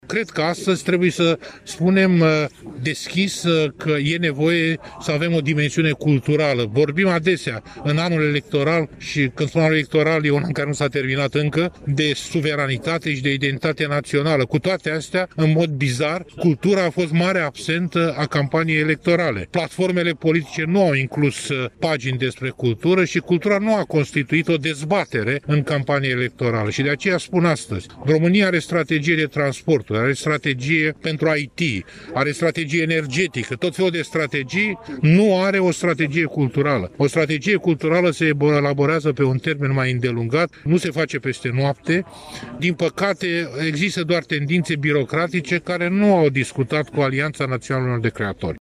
Președintele Uniunii Scriitorilor din România, Varujan Vosganian a anunțată astăzi, la Iași, că în acest an electoral, România are nevoie mai mult ca oricând de elaborarea unei startegii culturale și a solicitat Parlamentului suplimentarea fondurilor necesare funcționării Institutului Cultural Român.